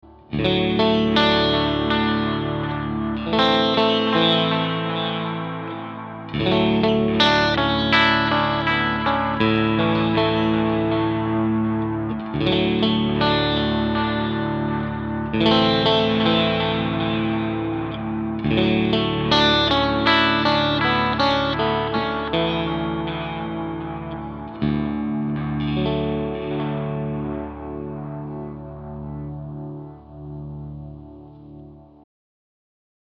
Soundbeispiele des Walrus Audio Mako D1
Alle Beispiele wurden mit meiner Fender Stratocaster und meinem Mesa Boogie Mark V 25 über den CabClone D.I. aufgenommen.